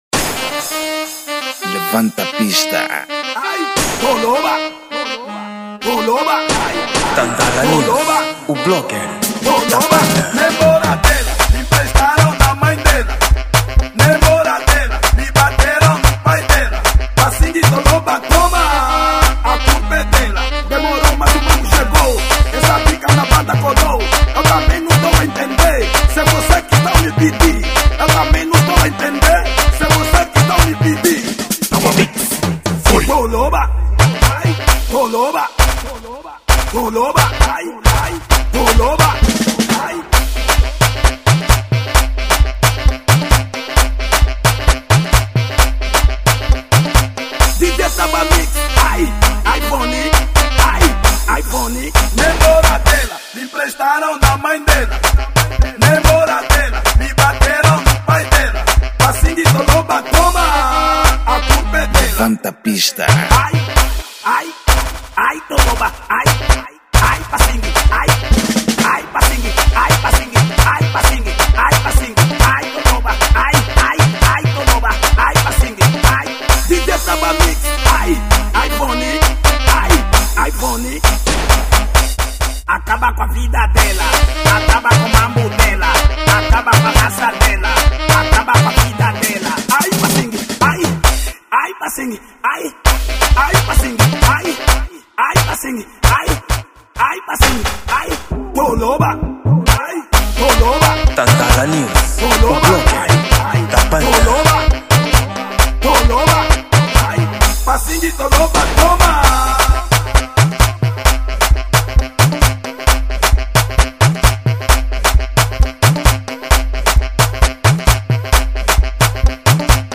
Gênero: Afro House